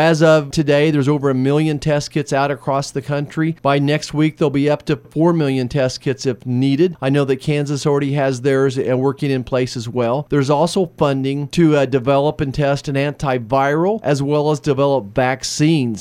Marshall, in an interview with KMAN Friday said money from the bill will also help support local governments respond to the disease.